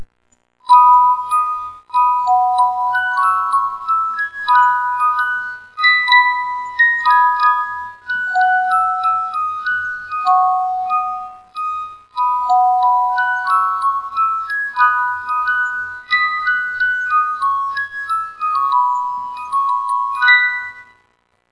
ここにまとめてオルゴールを録音してWAVEファイルにしてみました。
いまいちの音質です。
オルゴール]